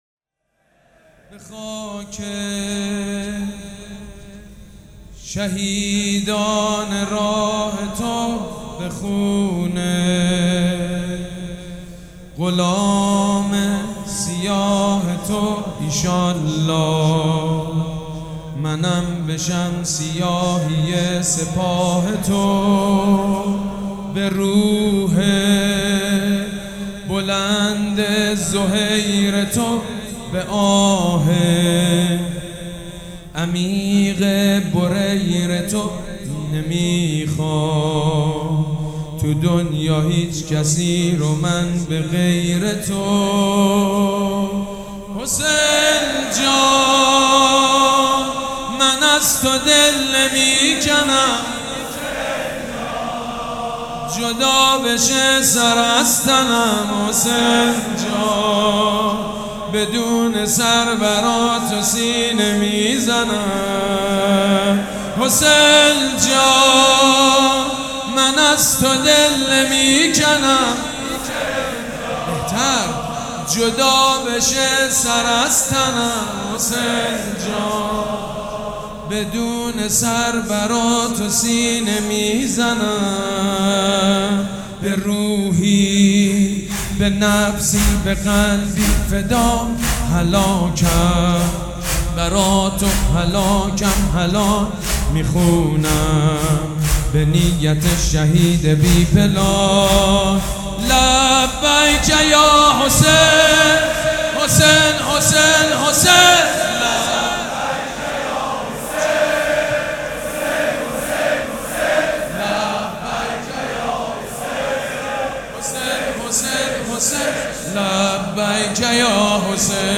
مداح
حاج سید مجید بنی فاطمه
مراسم عزاداری شب چهارم